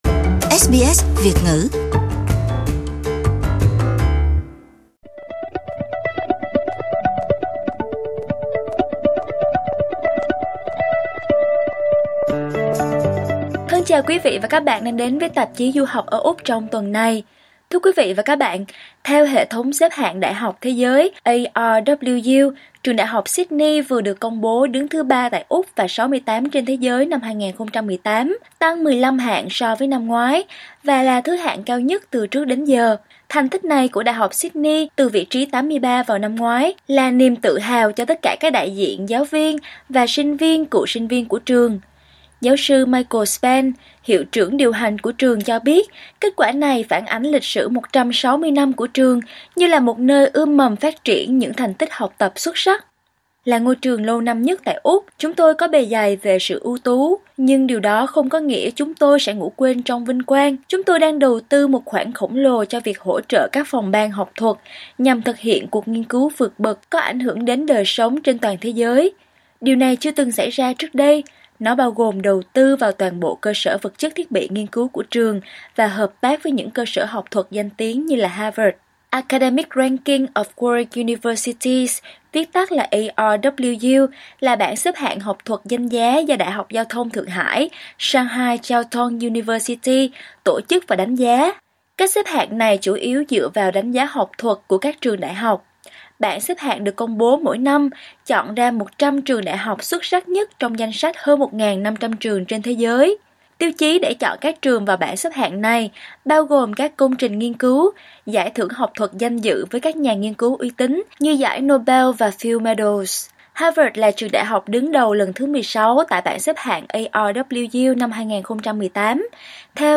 Cùng nghe cuộc bàn luận sôi nổi của du học sinh về vấn đề này.